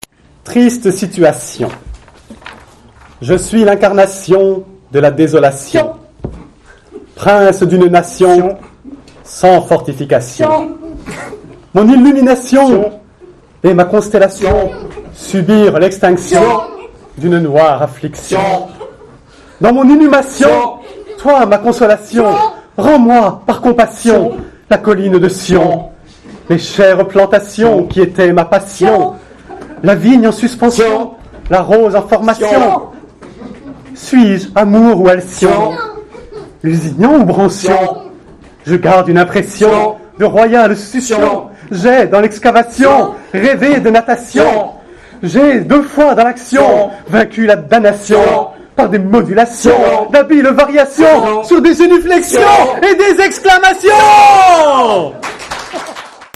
Lecture publique donnée le samedi 27 octobre 2001 par
tous en choeur